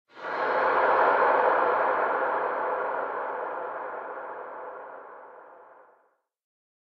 Звуки пещер из Майнкрафт
Находясь в пещерах Майнкрафт можно услышать множество разных тревожных и порой даже пугающих звуков.
Тоннельные вибрации